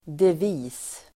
Uttal: [dev'i:s]